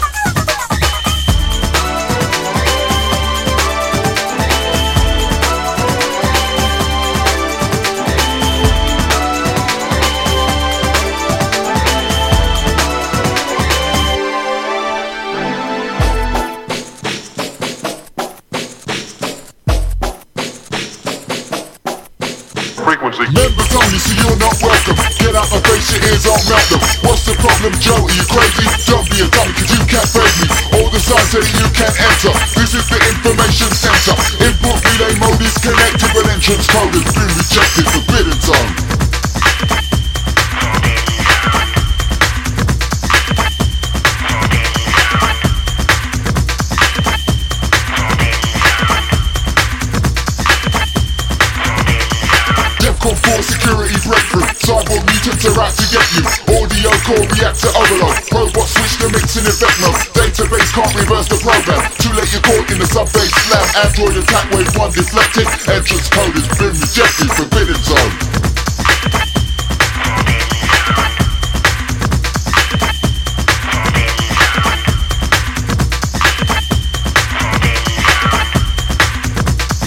Old Skool Hardcore / Old Skool Techno / Old Skool Breakbeat